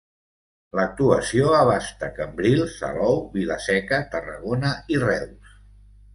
Pronounced as (IPA) [ˈsɛ.kə]